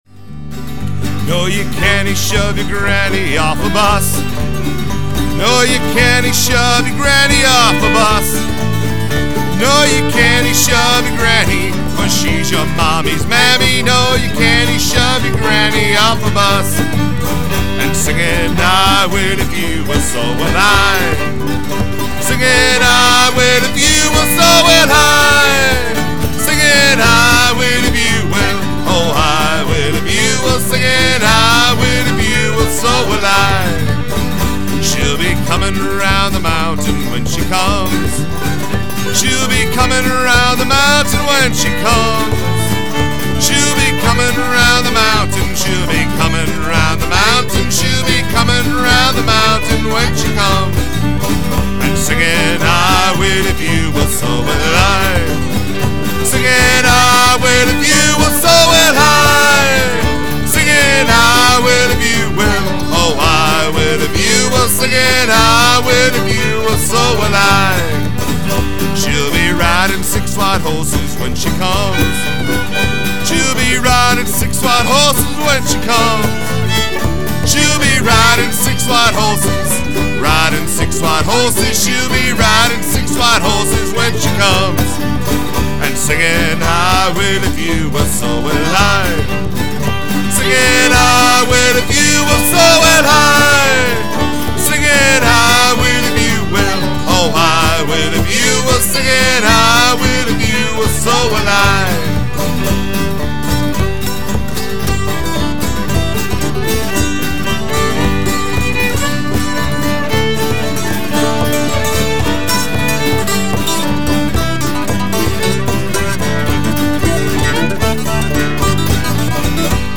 Chicago’s Premier Irish Band